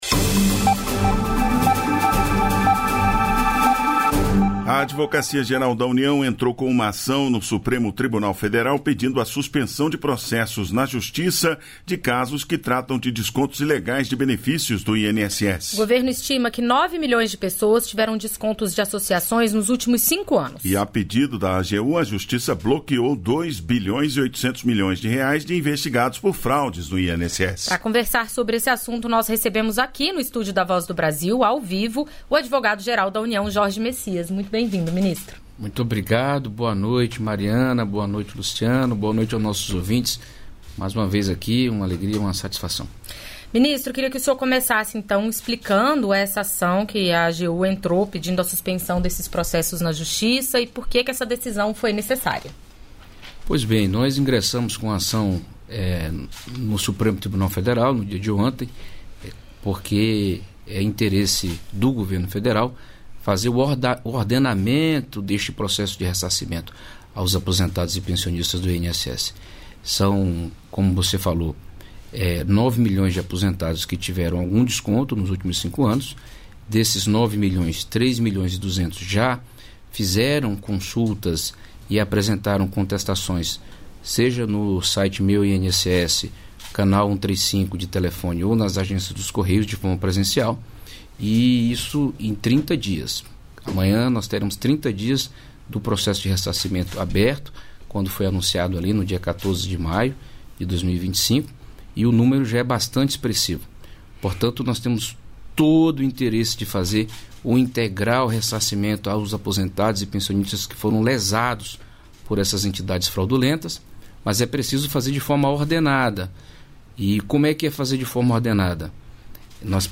Entrevistas da Voz
Jorge Messias, Advogado-Geral da União